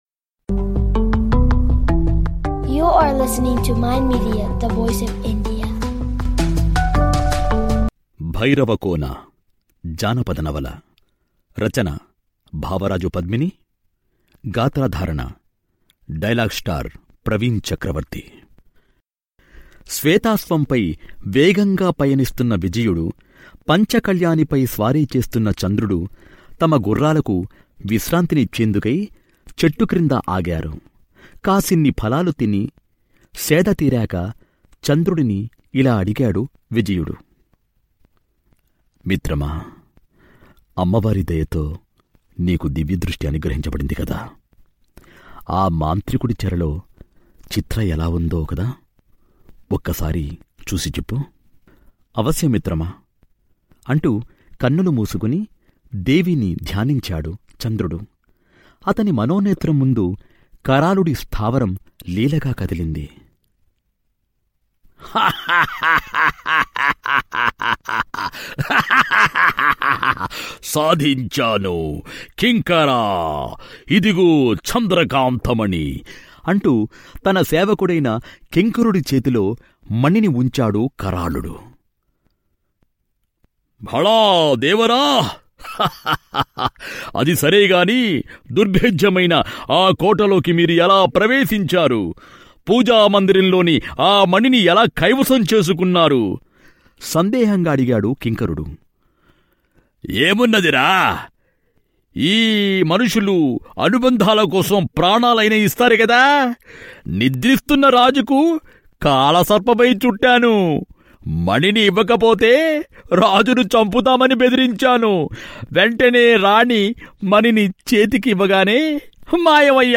Audio Books
Bhairavakona audio novel part 9.